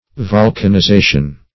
Meaning of volcanization. volcanization synonyms, pronunciation, spelling and more from Free Dictionary.
Volcanization \Vol`can*i*za"tion\, n.